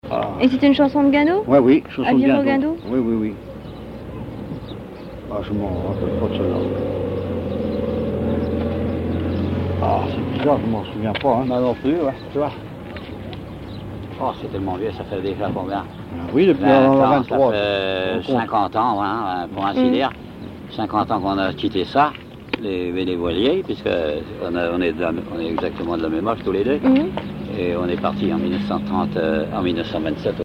Localisation Cancale
Catégorie Témoignage